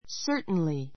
certainly 中 A2 sə́ː r tnli サ ～ ト ンり 副詞 ❶ 確かに, きっと perhaps 類似語 He'll certainly get well in a week or so.